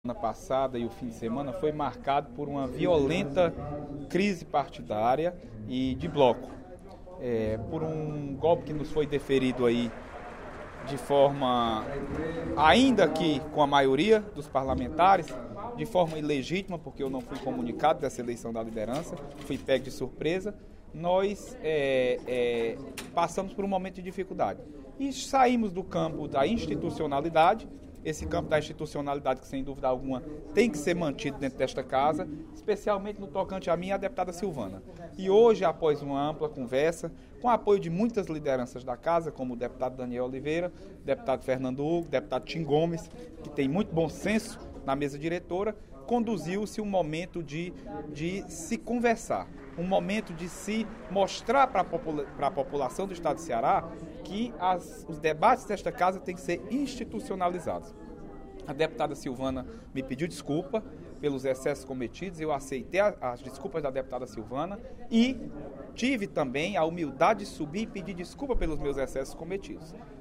O deputado Leonardo Araújo (PMDB) comentou, nesta terça-feira (13/06), sua destituição da liderança do bloco PMDB-PMB-PSD, para dar lugar à  deputada Dra. Silvana. O peemedebista falou no primeiro expediente da sessão plenária da Assembleia Legislativa.